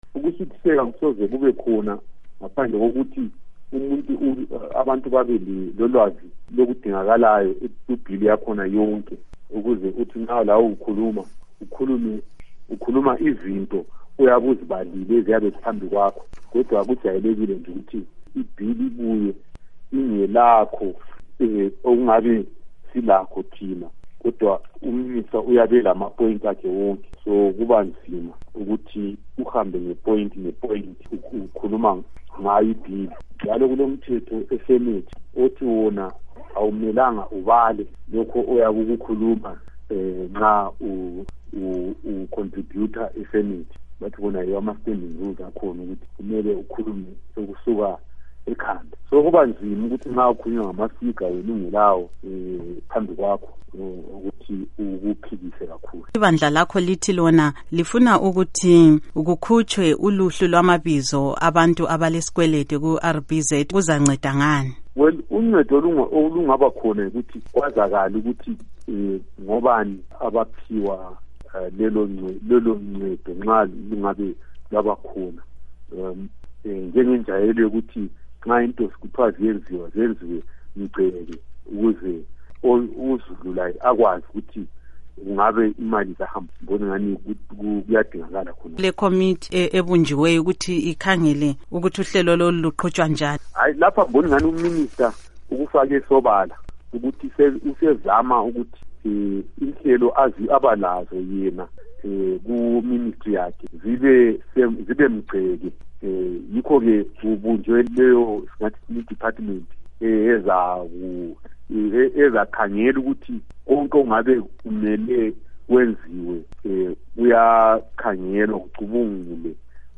Ingxoxo LoSenator Matson Hlalo